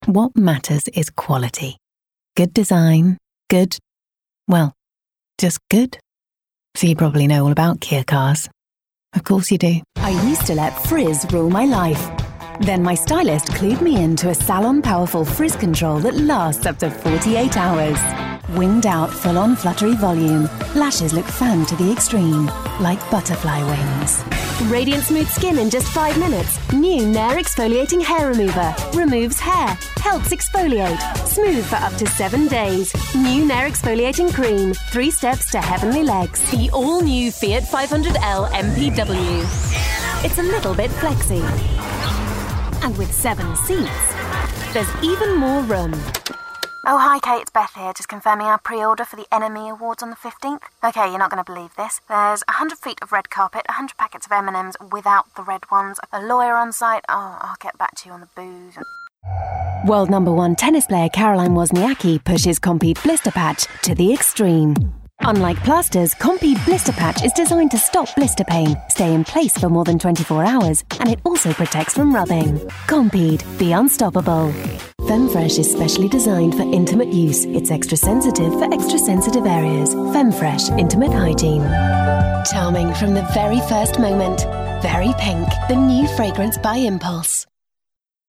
Commercial Showreel
London, RP ('Received Pronunciation'), Straight